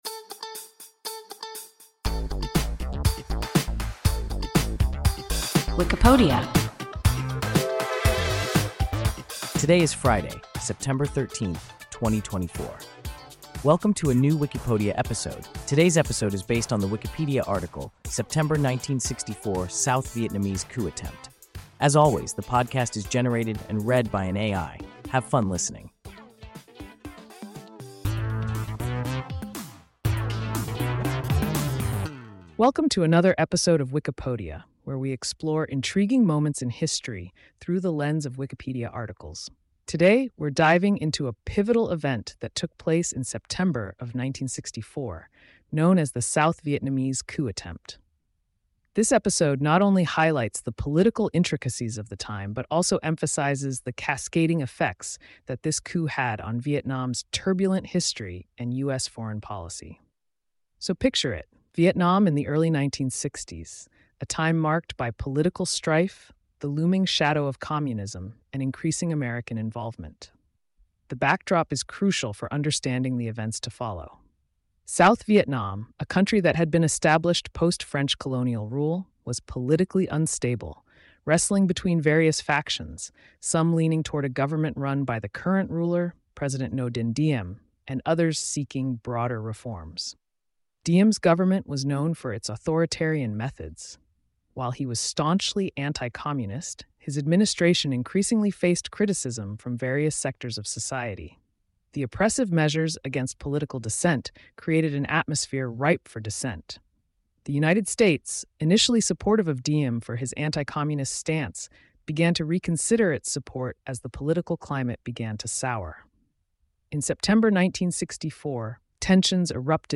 September 1964 South Vietnamese coup attempt – WIKIPODIA – ein KI Podcast